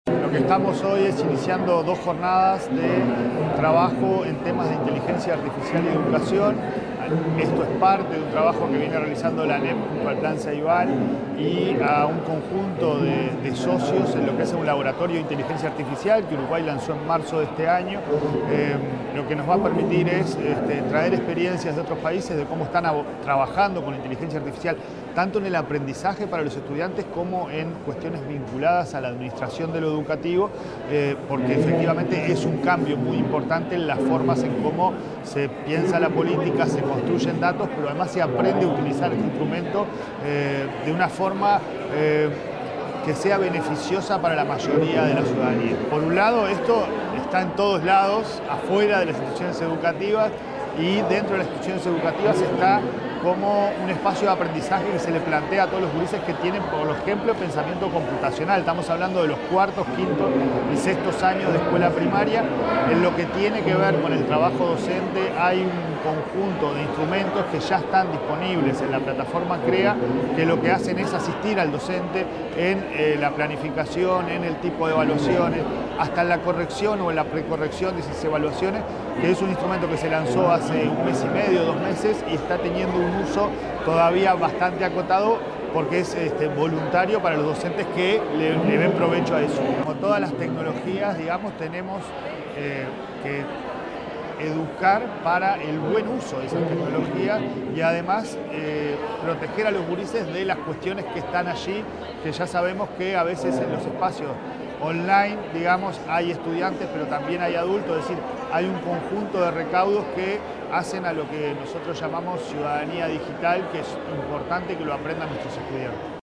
Declaraciones del presidente de la ANEP, Pablo Caggiani
Declaraciones del presidente de la ANEP, Pablo Caggiani 14/01/2025 Compartir Facebook X Copiar enlace WhatsApp LinkedIn El presidente de la Administración Nacional de Educación Pública (ANEP), Pablo Caggiani, explicó la importancia de incorporar la inteligencia artificial a la comunidad educativa. El jerarca participó en la apertura del congreso EduIA, organizado por Ceibal.